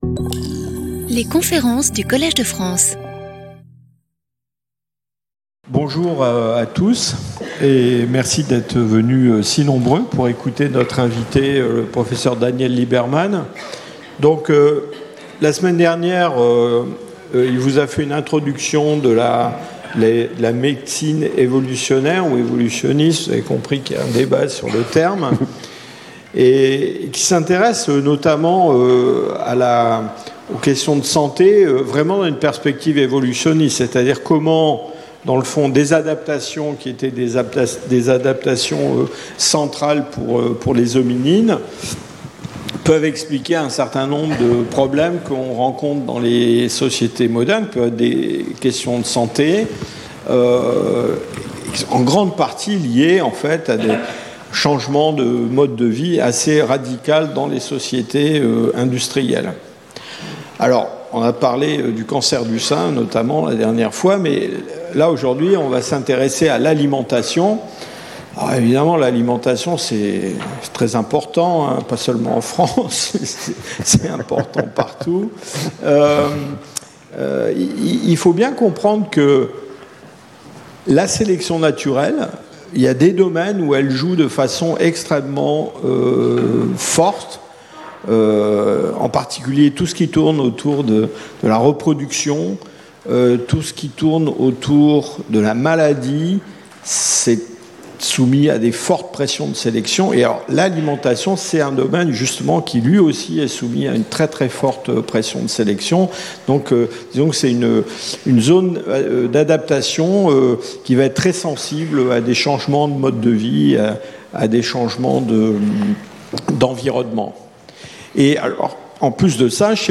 Guest lecturer